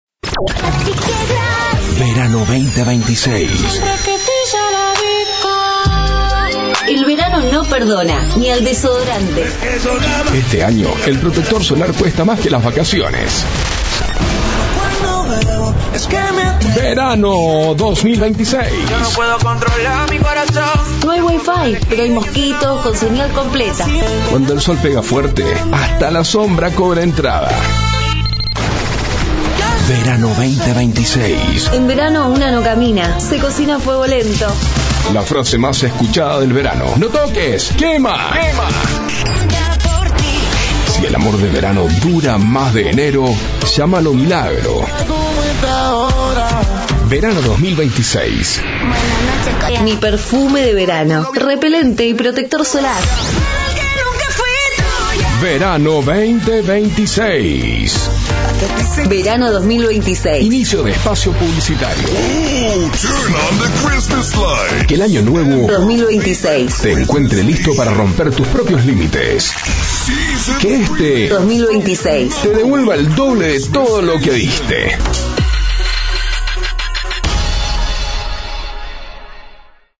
Artística de Verano confeccionada a UNA VOZ DINAMICA, JUVENIL, DIVERTIDA..
Editados con Música y Efectos
Con voz en OFF
Producción confeccionada a 1 voz